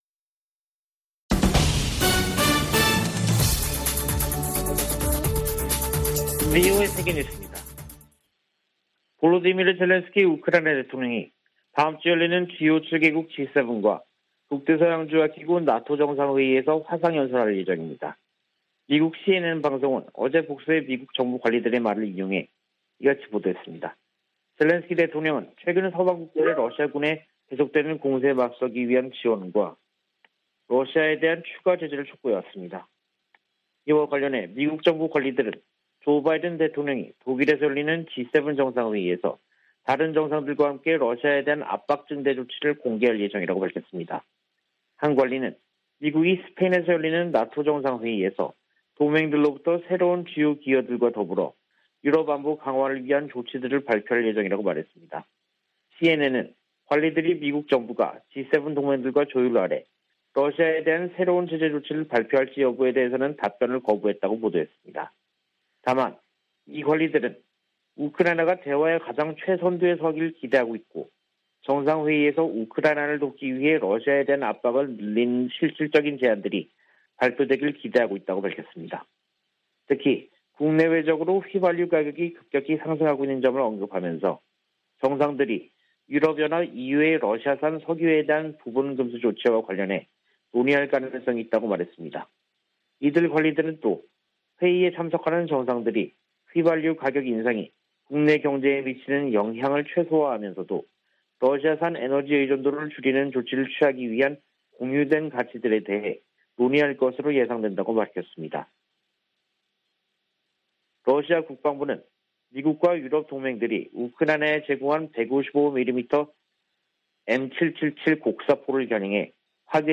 VOA 한국어 간판 뉴스 프로그램 '뉴스 투데이', 2022년 6월 23일 3부 방송입니다. 윤석열 한국 대통령이 이달 말 나토 정상회의에 참석할 예정인 가운데, 미 국무부는 한국을 나토의 중요한 파트너라고 언급했습니다. 나토는 사이버·비확산 분야 등 한국과의 협력 관계 증진에 대한 기대감을 나타냈습니다. 미 하원 군사위원회가 강력한 주한미군 유지 중요성을 강조하는 내용 등이 담긴 새 회계연도 국방수권법안을 공개했습니다.